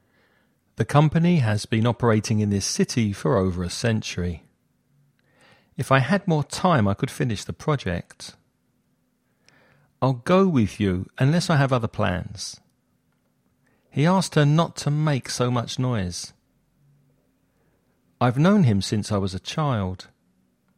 Listening Practice
You’re going to listen to a man talking about a day at the seaside.